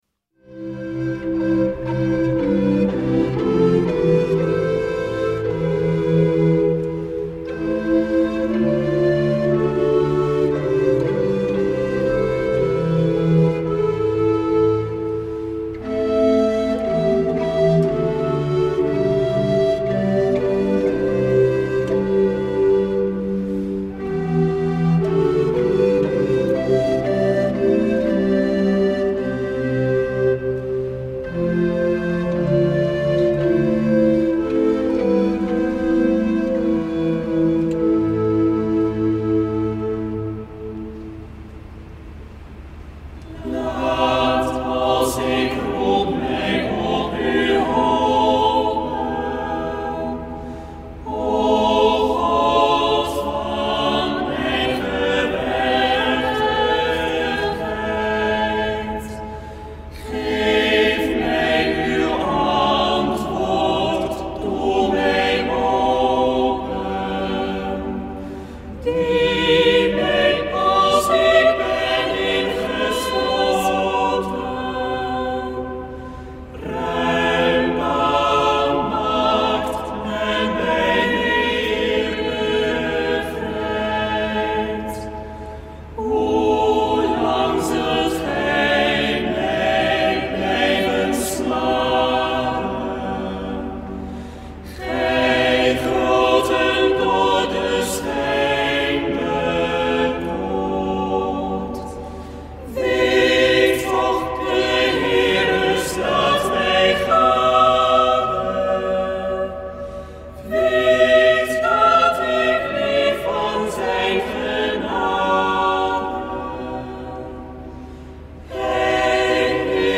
Vandaag een aantal bewerkingen over psalm 4.
En uit de Russisch orthodoxe liturgie enkele chants.
Opening van deze zondag met muziek, rechtstreeks vanuit onze studio.